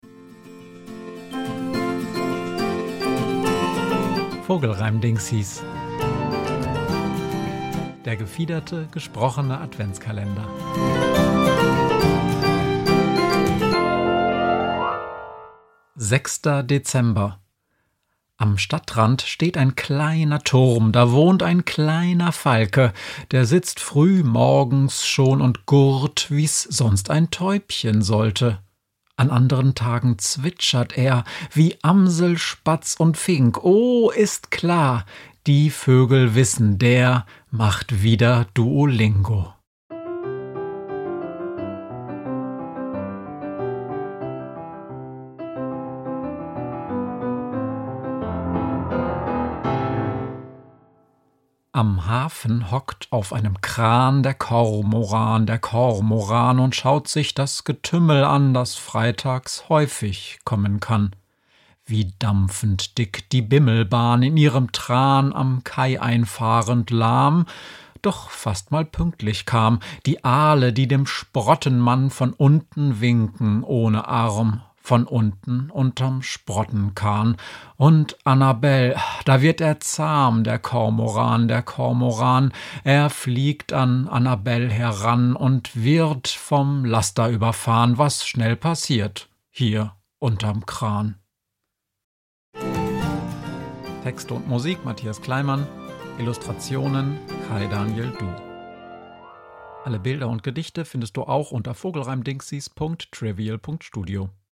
gefiederte, gesprochene Adventskalender